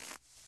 added base steps sounds
snow_1.ogg